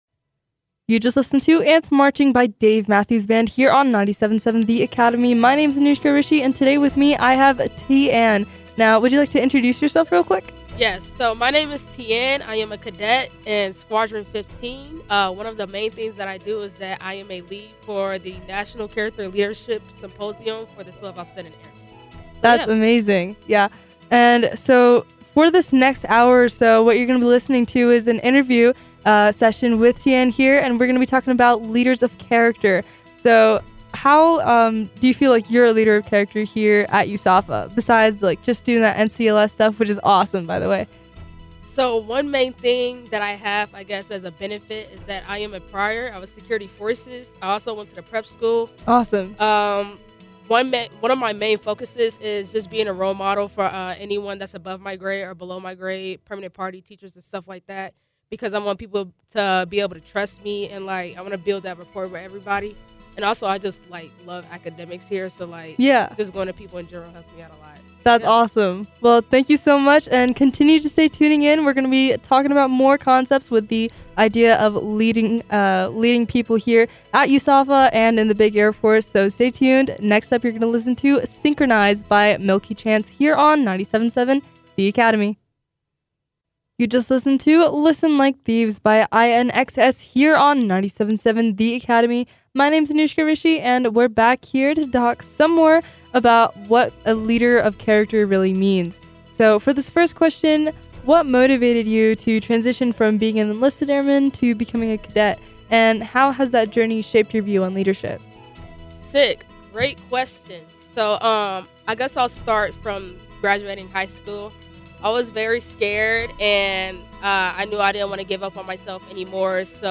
KAFA Cadet Interview